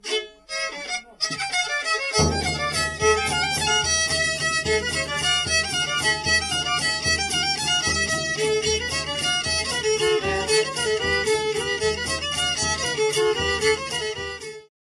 Tę wypowiedź przyjęliśmy jako motto dla płyty prezentującej najstarsze archiwalne nagrania ludowej muzyki skrzypcowej ze zbiorów Instytutu Sztuki.